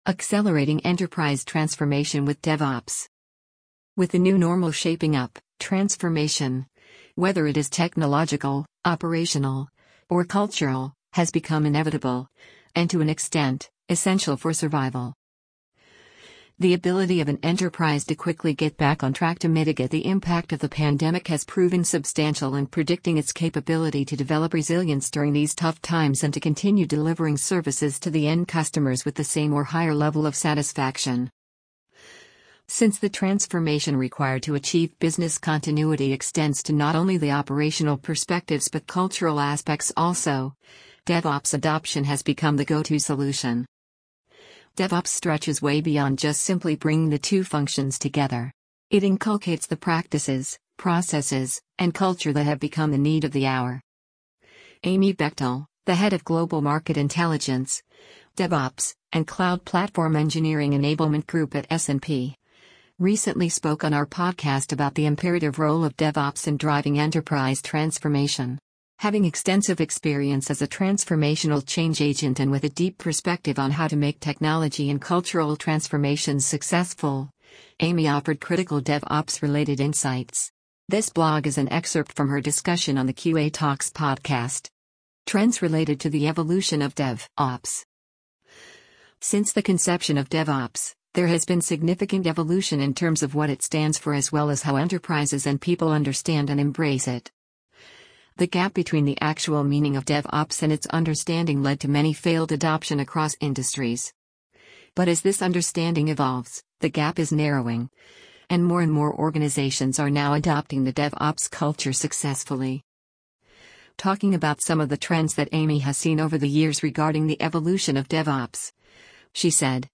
amazon_polly_14970.mp3